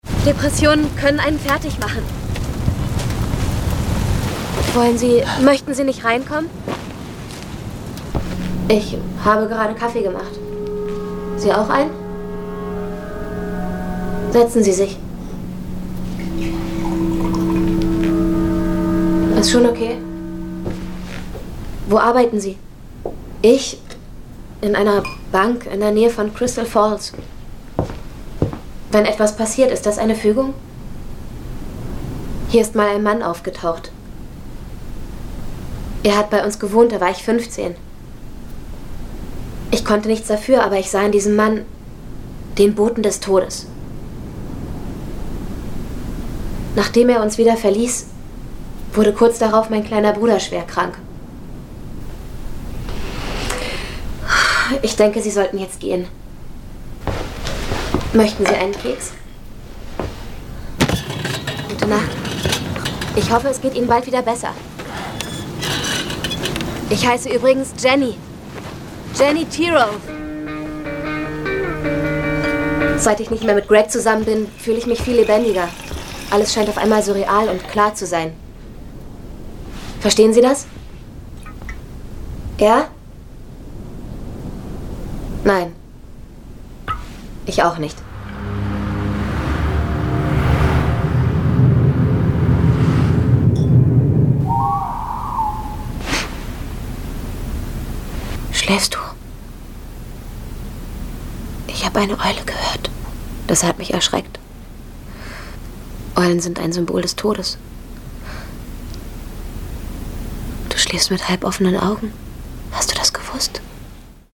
deutsche Sprcherin, schön, jung, klar, flexibel
Sprechprobe: Werbung (Muttersprache):
german female voice over artist